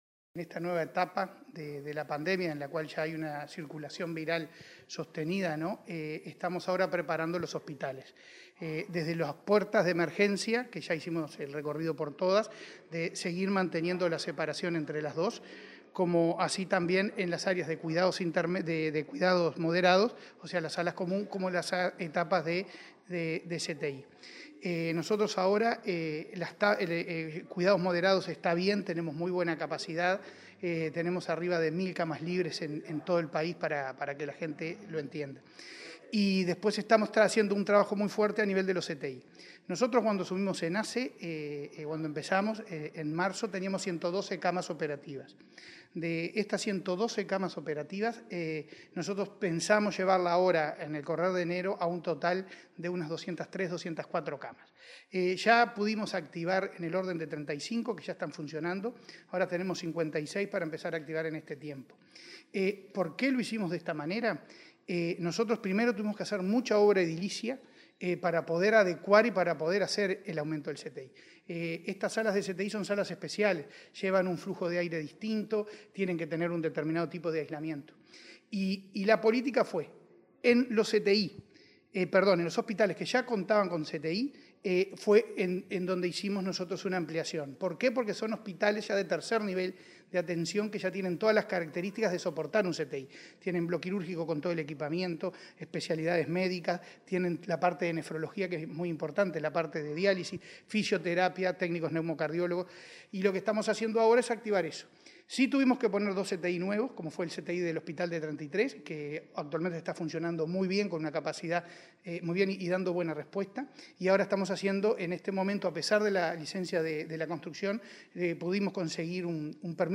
Cipriani en entrevista con Comunicación Presidencial sobre aumento de camas de CTI y ambulancias especializadas